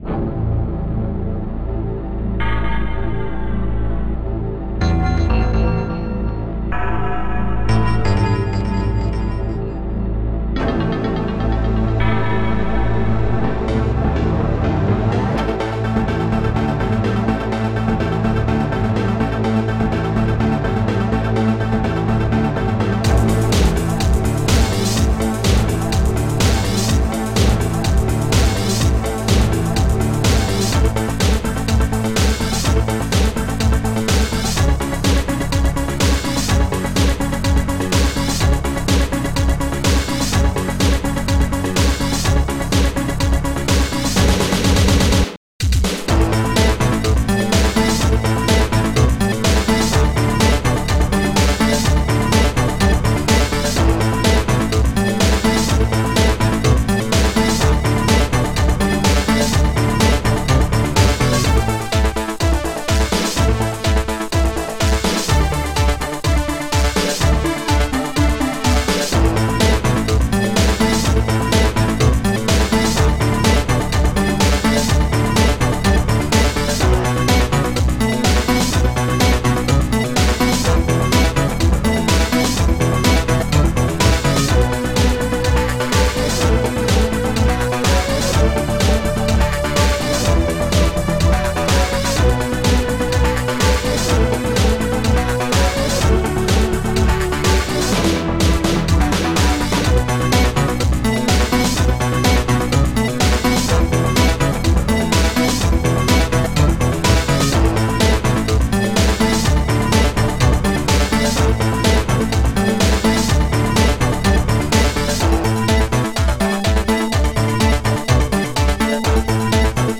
ST-01:heavbass
ST-01:brightbrass4
ST-11:housesynth